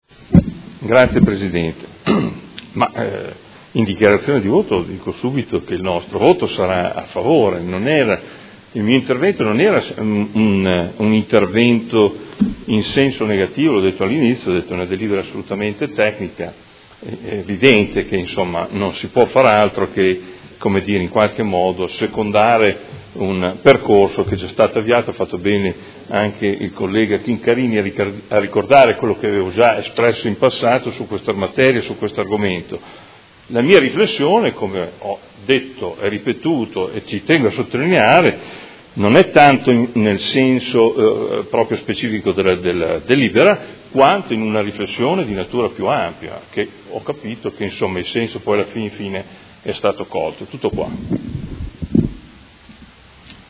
Adolfo Morandi — Sito Audio Consiglio Comunale
Seduta del 21/06/2018 Dichiarazione di voto.